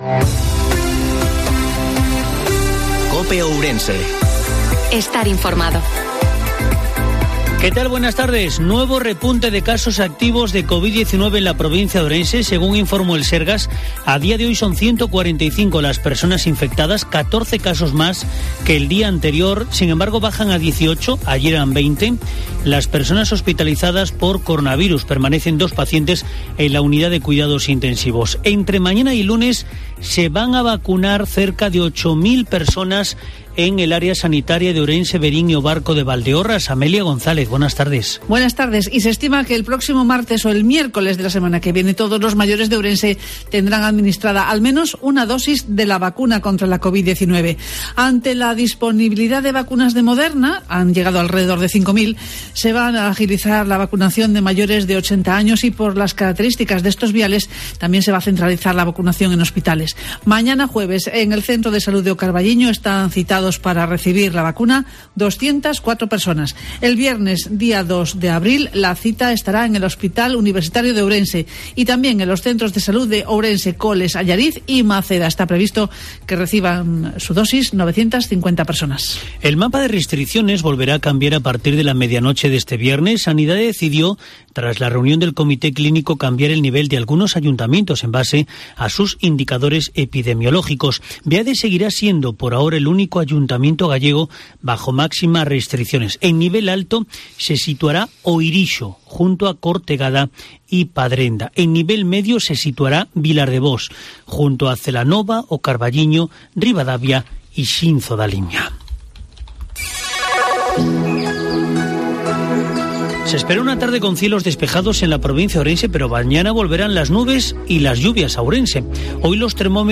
INFORMATIVIO MEDIODIA COPE OURENSE